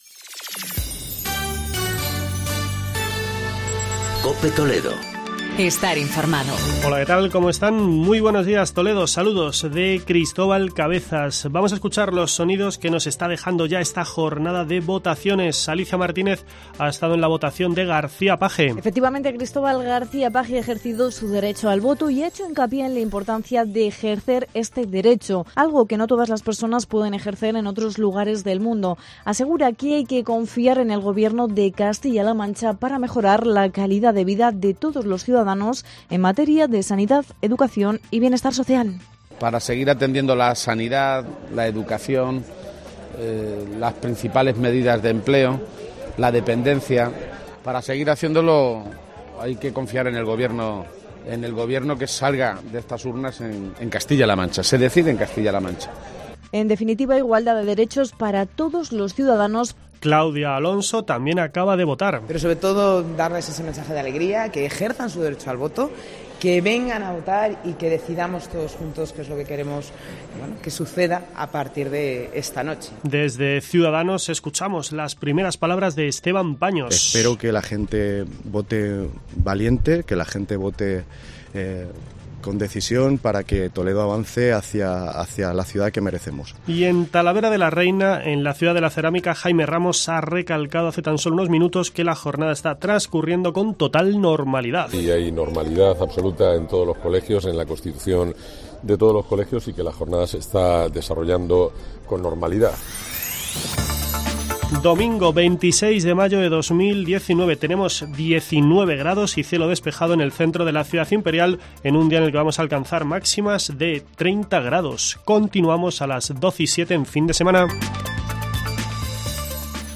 Declaraciones a los medios a su llegada al colegio Ciudad de Nara de Toledo